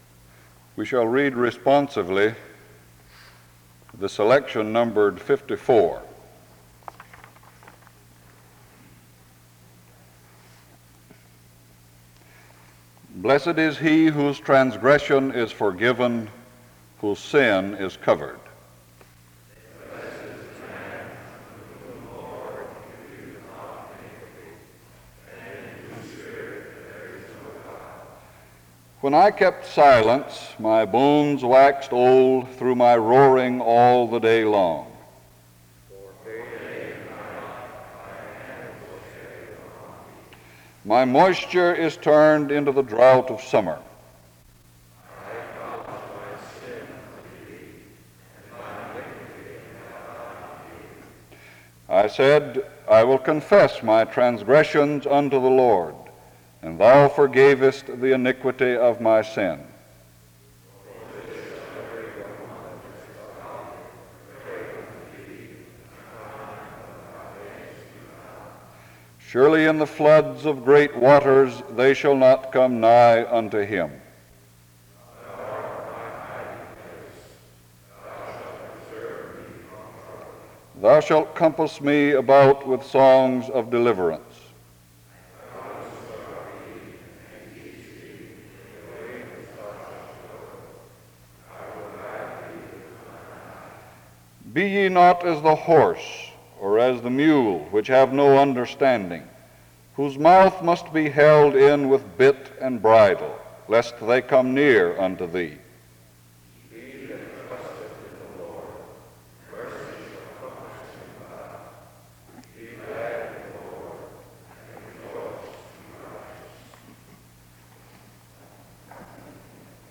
The service begins with a responsive reading from 0:00-1:58. An introduction to the speaker is given from 2:02-4:36.
SEBTS Chapel and Special Event Recordings SEBTS Chapel and Special Event Recordings